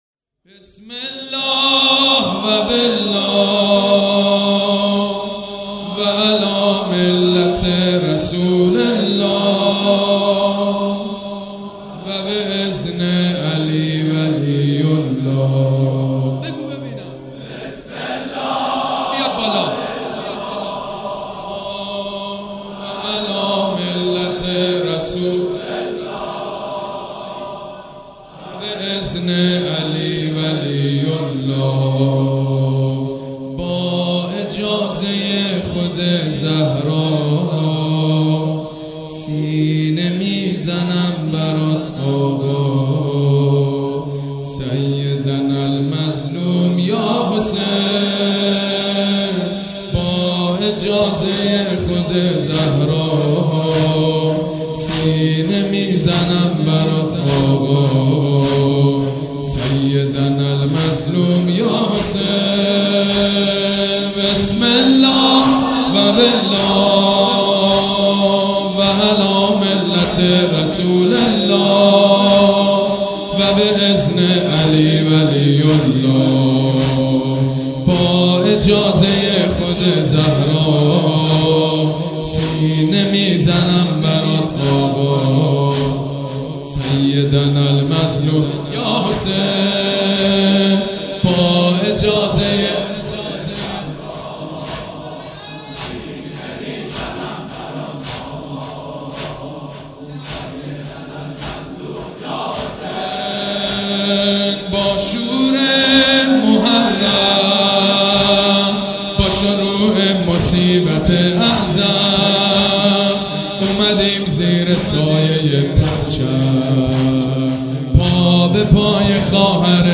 صوت مراسم شب چهارم محرم 1438هیئت ریحانة الحسین(ع) ذیلاً می‌آید: